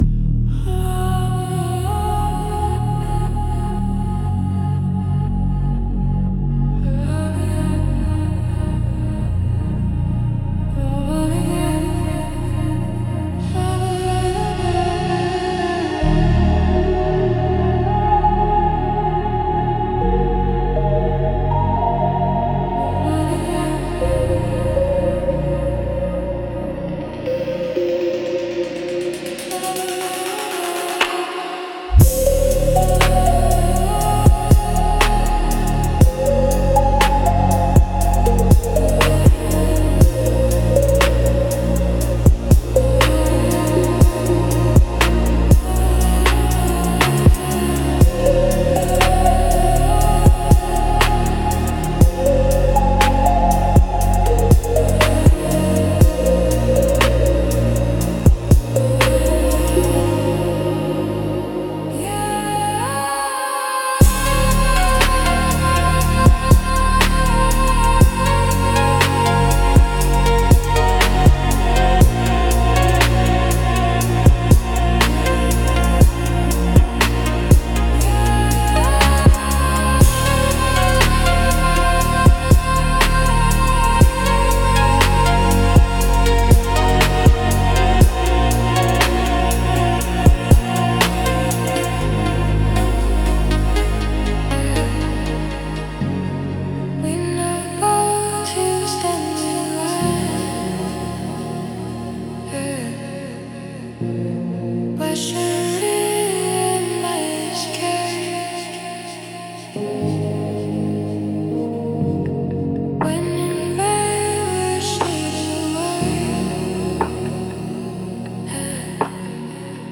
Instrumental - Bassline Meditation 3.13